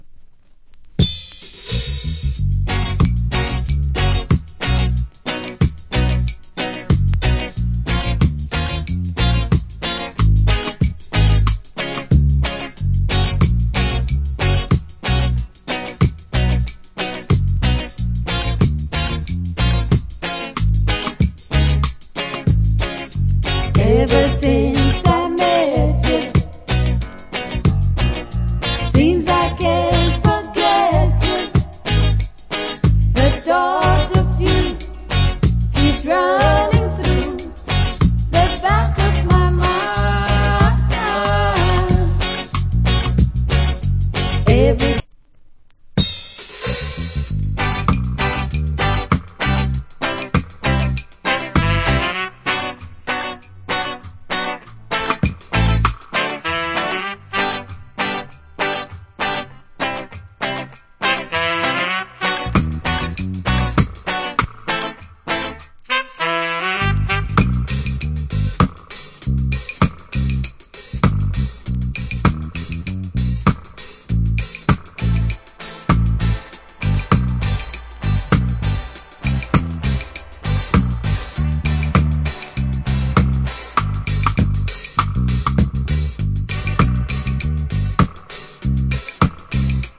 Nice Vocal.